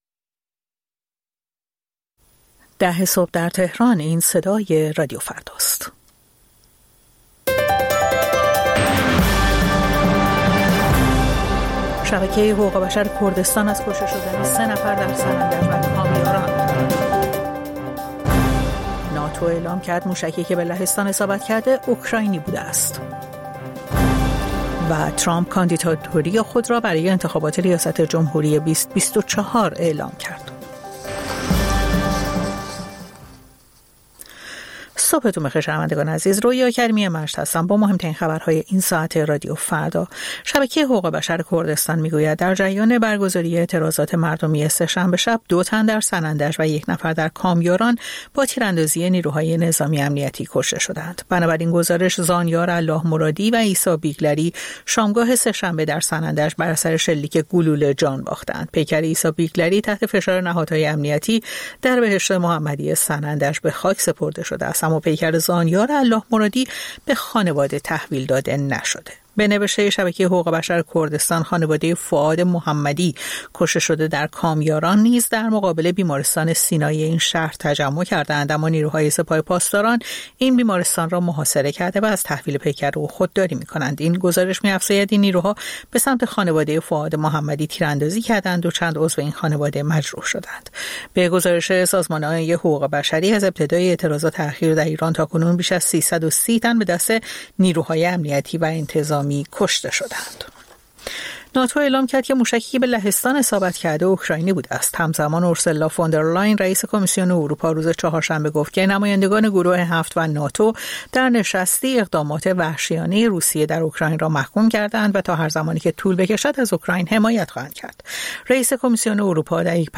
سرخط خبرها ۱۰:۰۰